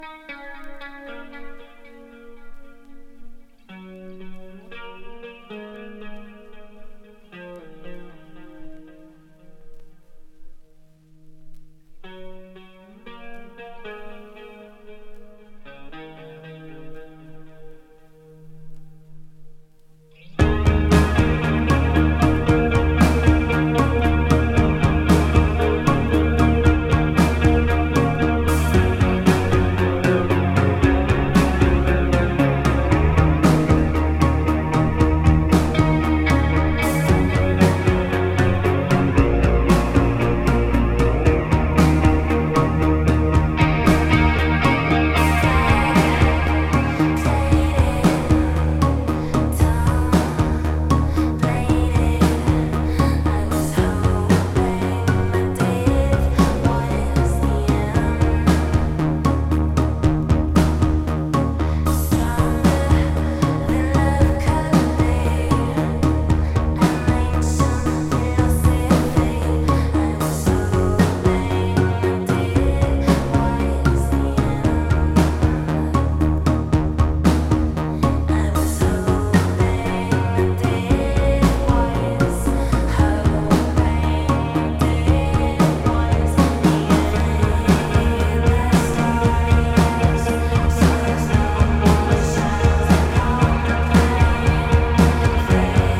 Darkwave, Goth Rock要素を取り入れたサウンドに、親しみやすいメロディーが素晴らしい！